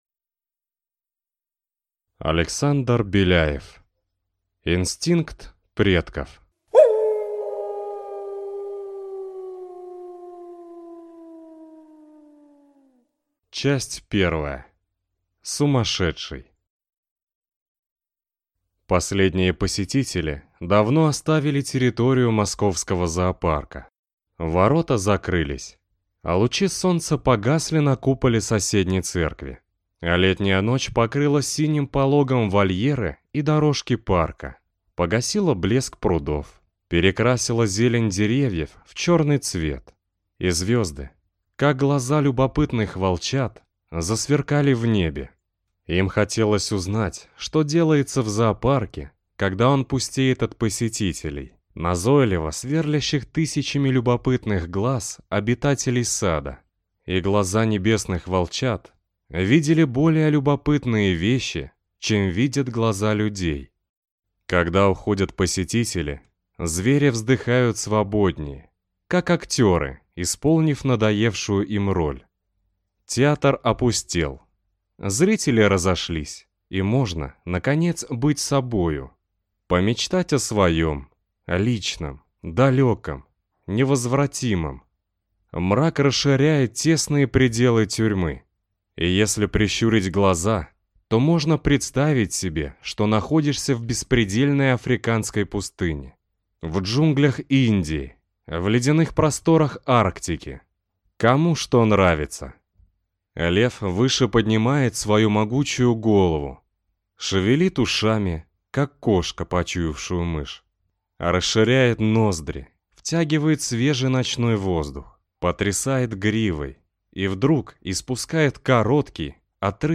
Аудиокнига Инстинкт предков | Библиотека аудиокниг